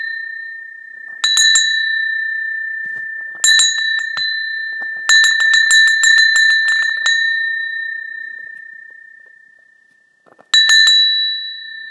Litinový zvonek bílý Welcome